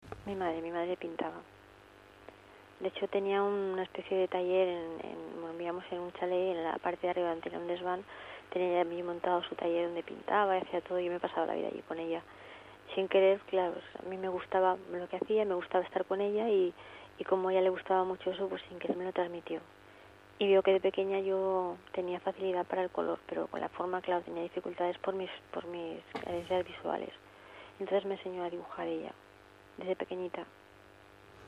suena cercana, increíblemente dulce, aniñada, con colores de tonos suaves, casi infantiles.